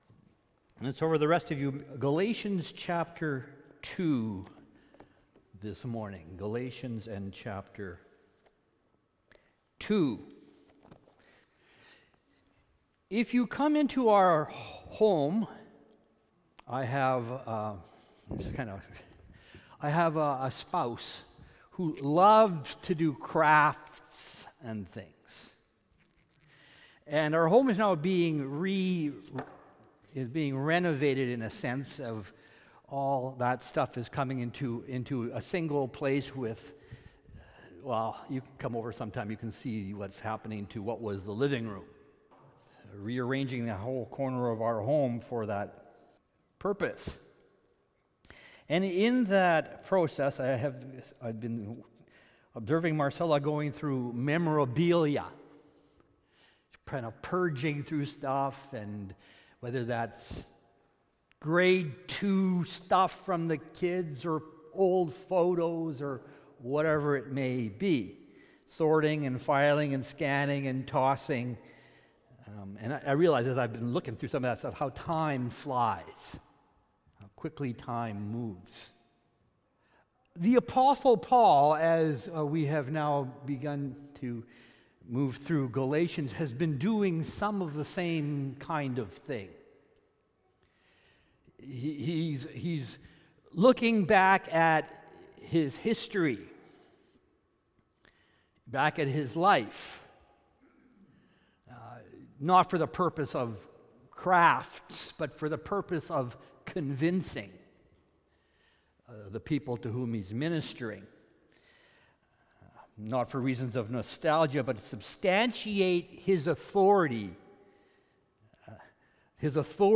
Sermons | Richmond Alliance Church